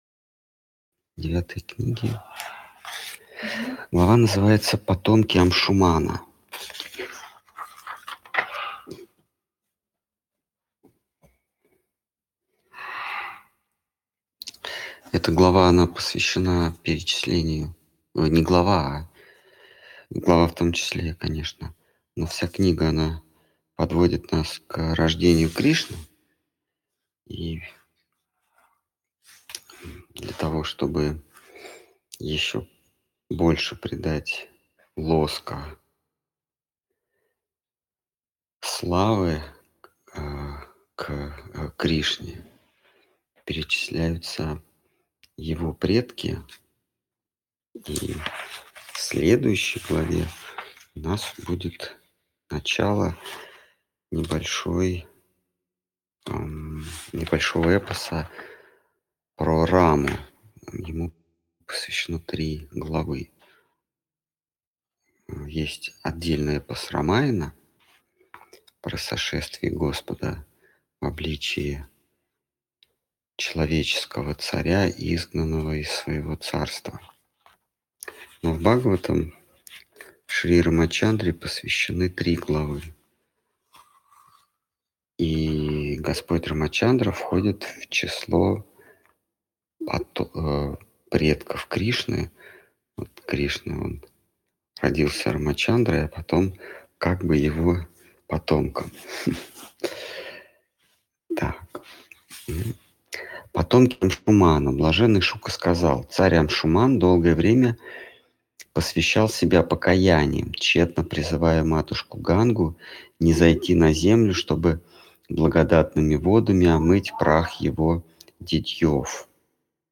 Ответы на вопросы из трансляции в телеграм канале «Колесница Джаганнатха». Тема трансляции: Шримад Бхагаватам.